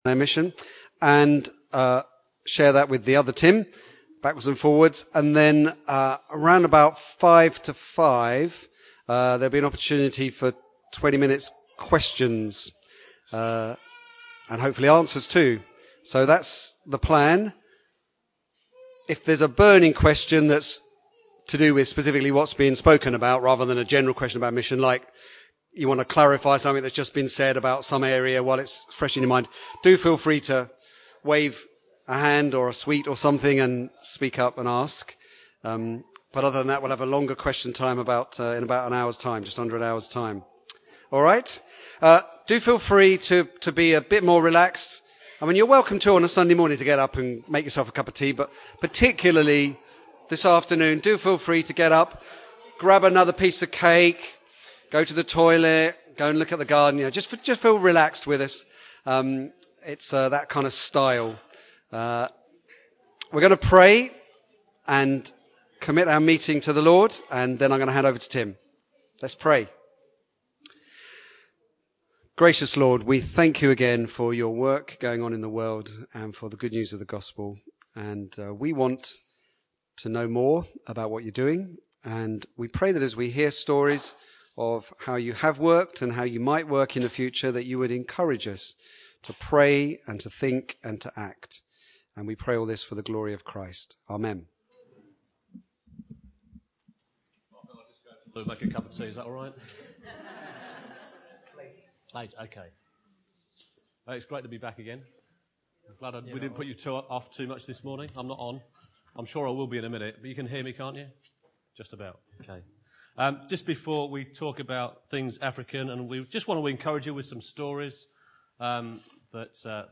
Africa Inland Mission We listened to guest speakers from Africa Inland Mission discuss the mission field in Africa.